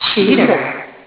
"Cheater" sound. (AU format, not as good quality)